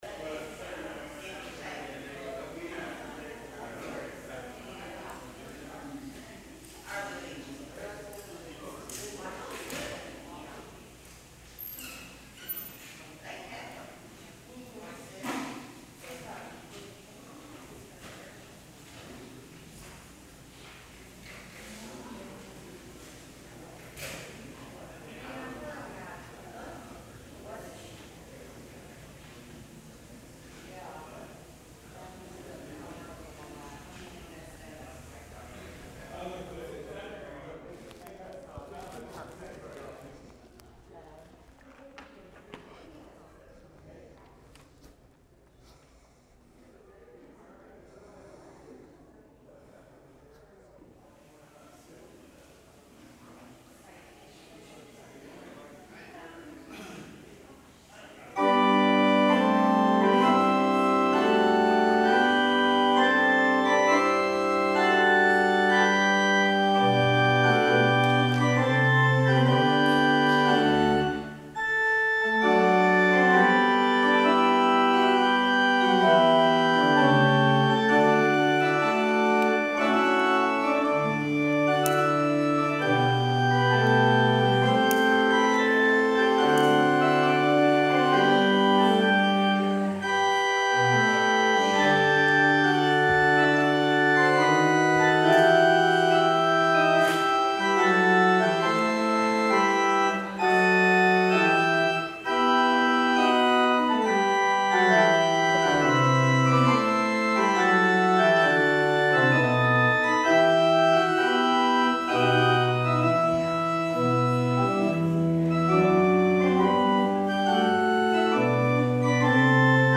June 30, 2019 Service
Traditional Sermon